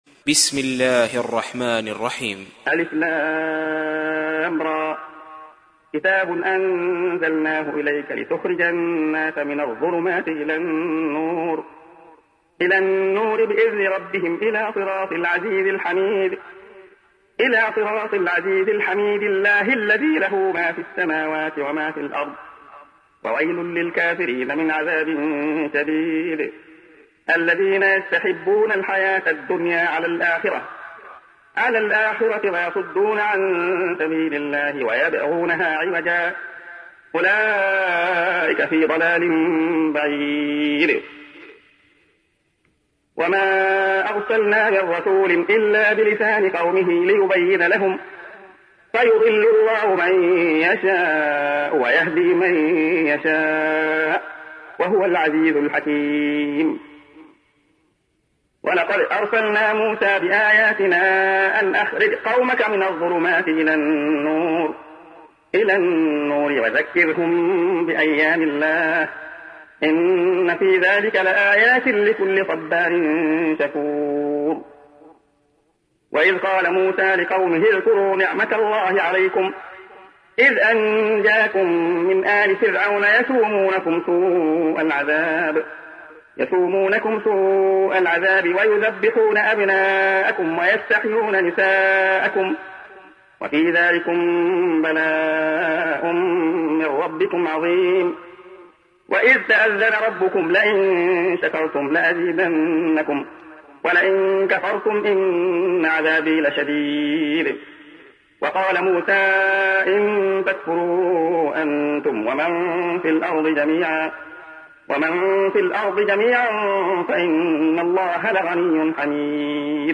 تحميل : 14. سورة إبراهيم / القارئ عبد الله خياط / القرآن الكريم / موقع يا حسين